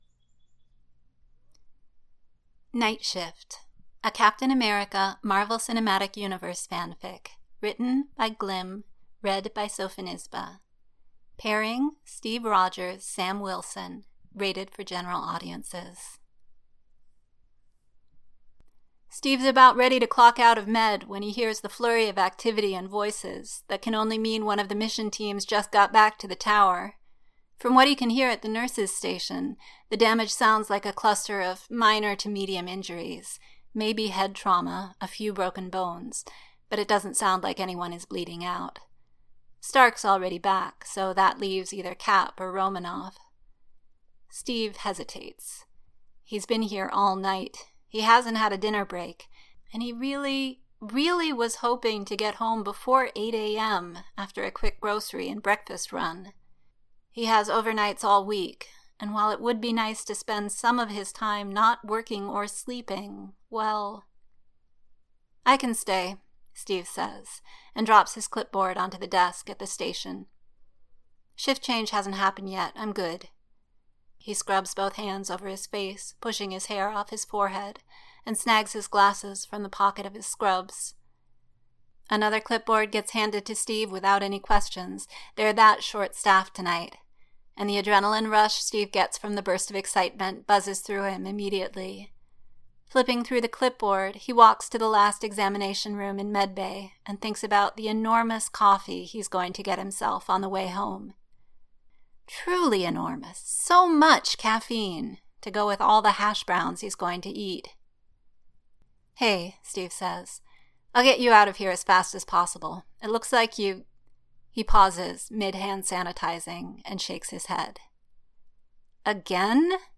[Podfic] night shift